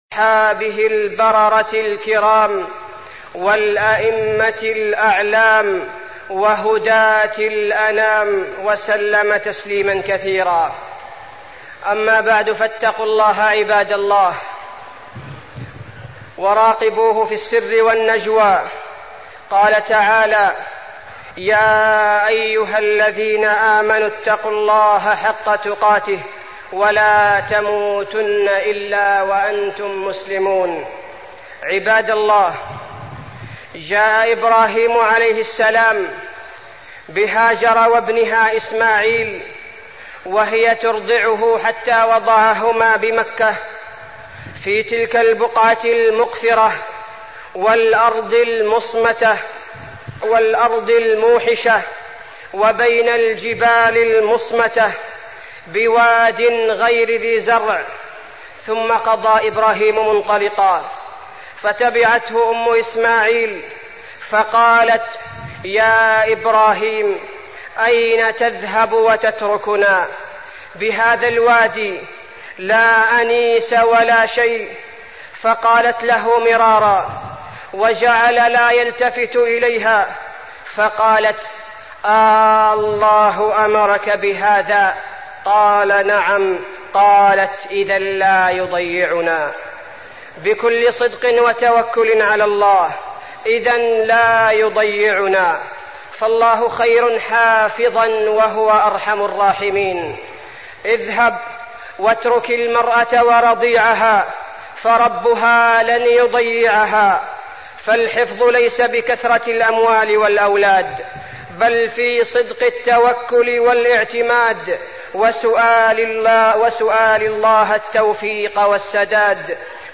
خطبة حكمة الحج وفيها: قصة هاجر وإسماعيل رضي الله عنهم، وفضل ماء زمزم، وحجة النبي صلى الله عليه وسلم، وصية لكل حاج
تاريخ النشر ٦ ذو الحجة ١٤٠٦ المكان: المسجد النبوي الشيخ: فضيلة الشيخ عبدالباري الثبيتي فضيلة الشيخ عبدالباري الثبيتي حكمة الحج